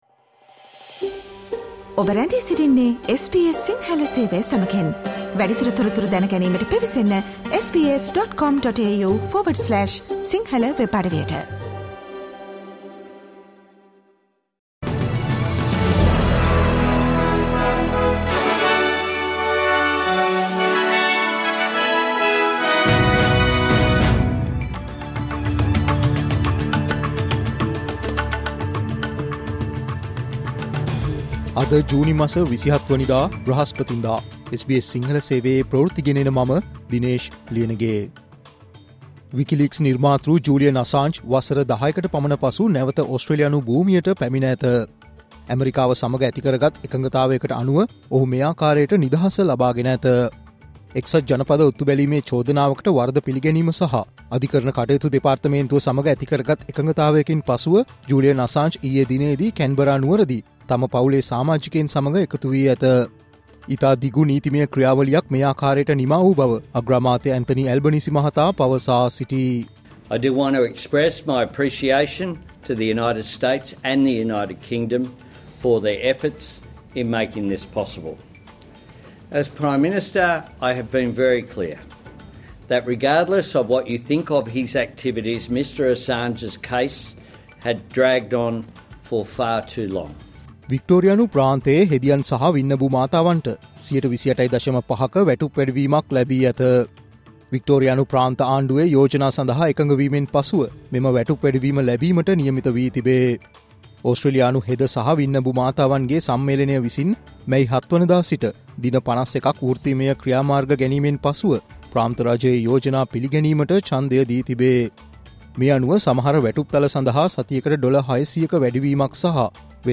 Australia's news in Sinhala.
Get the news of the day at a glance - Listen to the news flash brought by SBS Sinhala program on Mondays, Tuesdays, Thursdays and Fridays at 11am Australian time Share